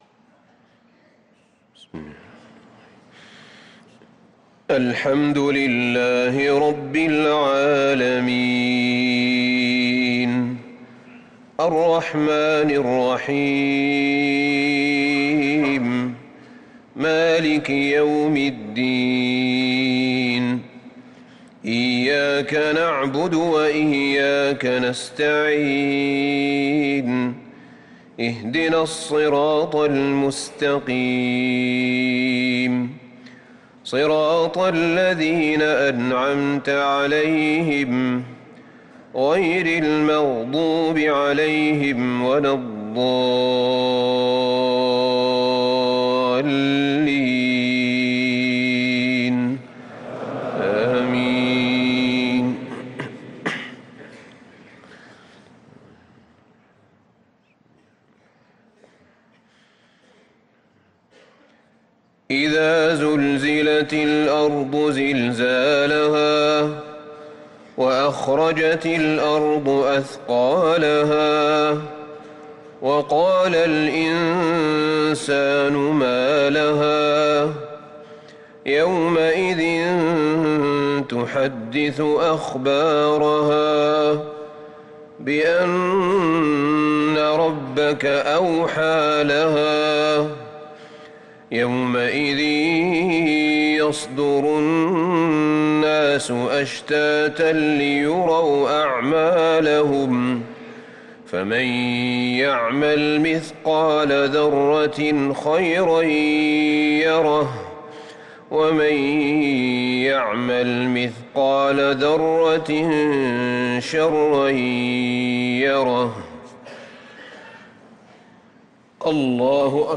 صلاة المغرب للقارئ أحمد بن طالب حميد 22 شوال 1444 هـ
تِلَاوَات الْحَرَمَيْن .